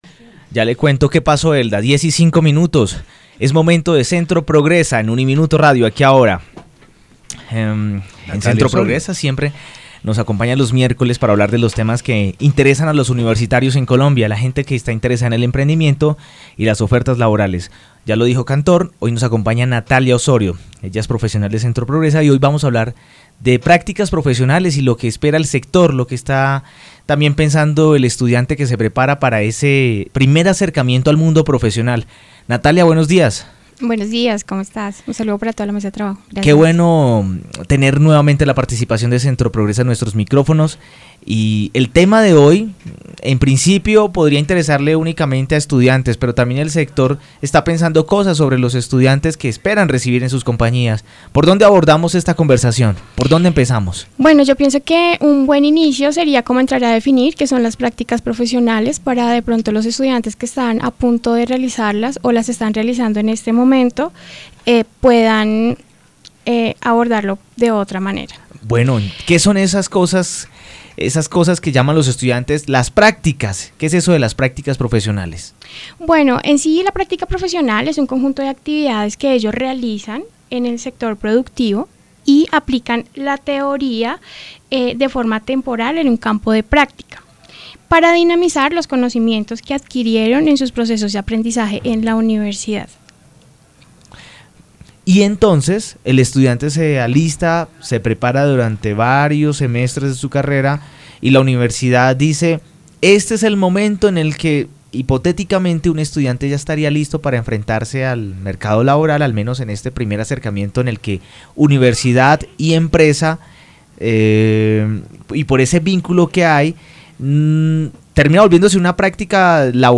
En dialogo con Aquí y Ahora dejaron varios tips a tener en cuenta.